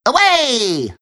Worms speechbanks
runaway.wav